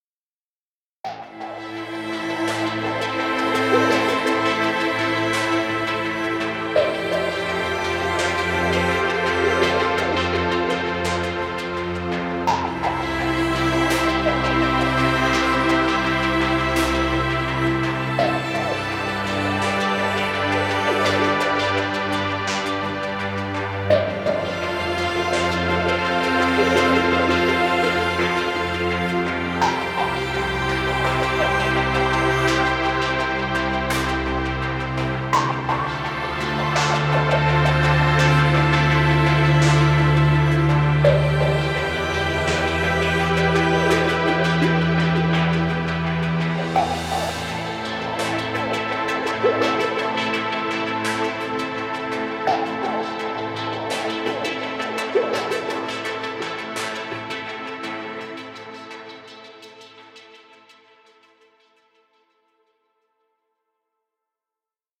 Ambient music. Background music Royalty Free.